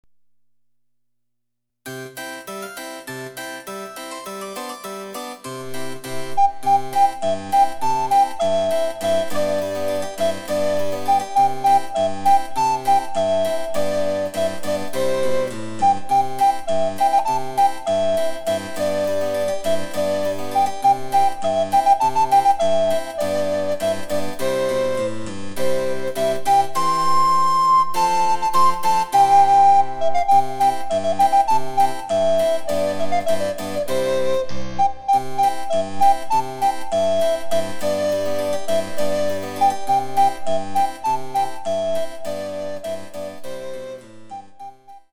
チェンバロ伴奏で楽しむ日本のオールディーズ、第５弾！
※伴奏はモダンピッチのみ。